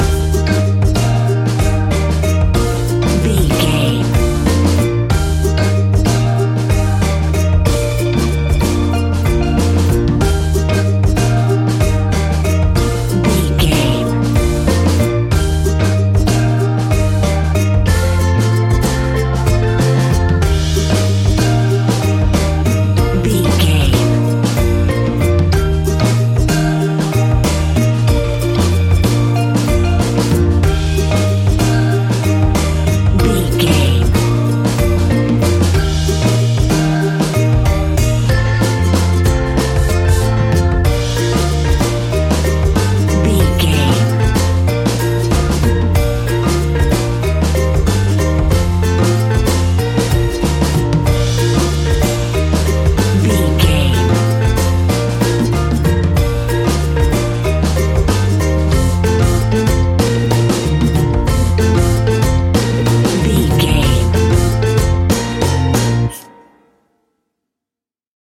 A warm and fun piece of calypso reggae style fusion music.
That perfect carribean calypso sound!
Ionian/Major
B♭
steelpan
drums
bass
brass
guitar